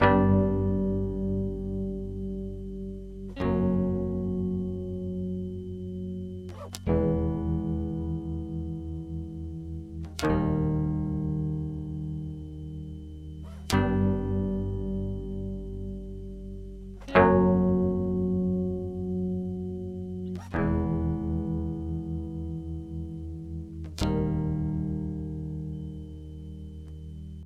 木吉他
描述：一个简单的吉他旋律
Tag: 130 bpm Trap Loops Guitar Acoustic Loops 2.48 MB wav Key : Unknown FL Studio